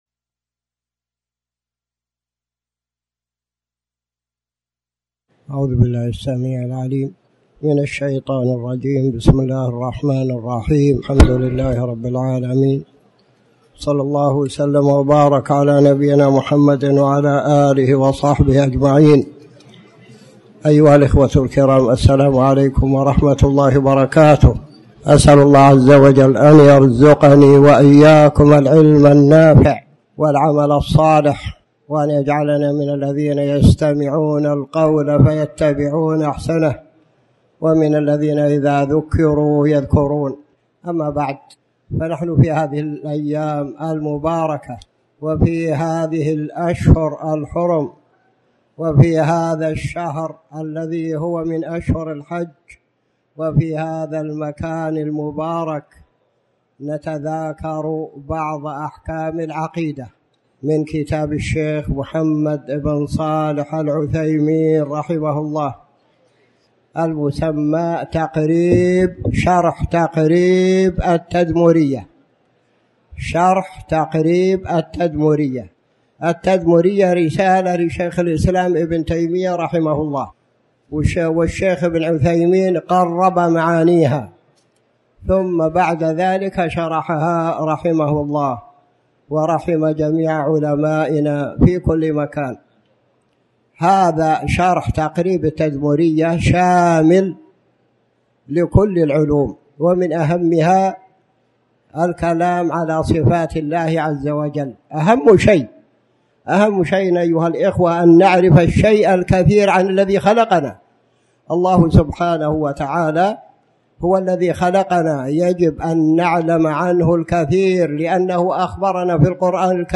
تاريخ النشر ١٨ ذو القعدة ١٤٣٩ هـ المكان: المسجد الحرام الشيخ